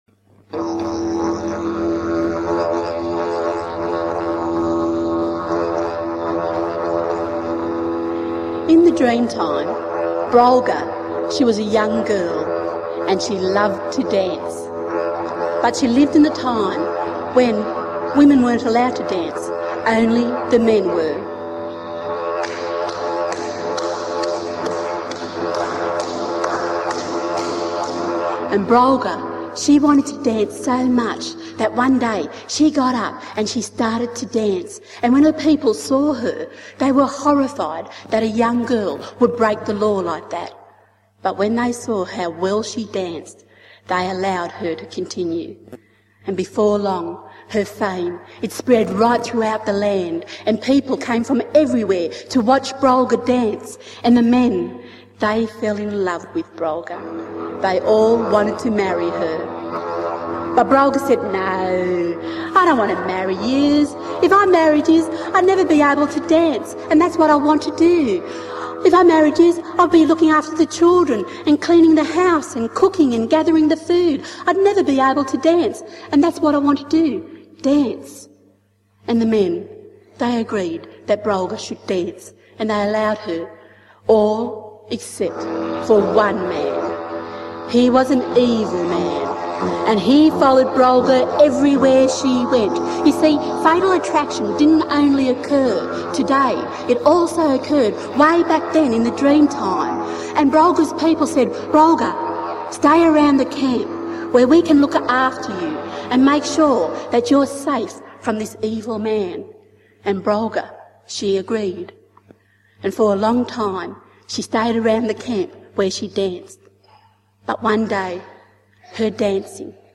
Dreamtime storytelling